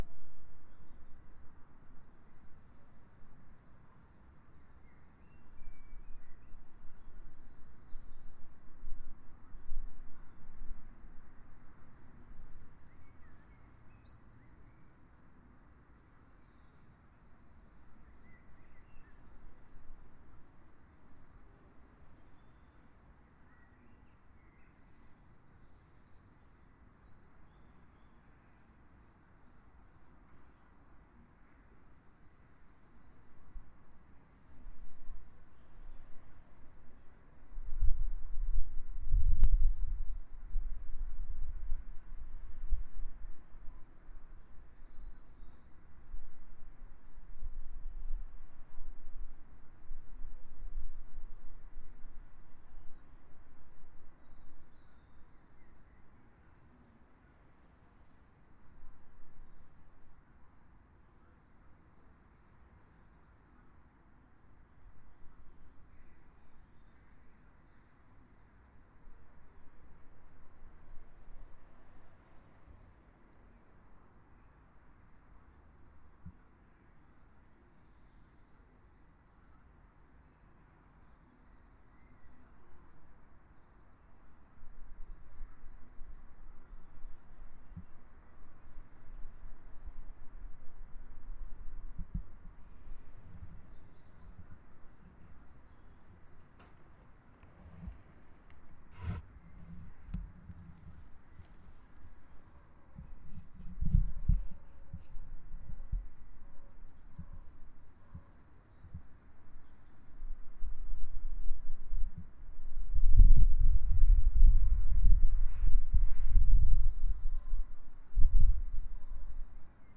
Sunnyboo 7. Final birdsong boo for now. Also includes distant train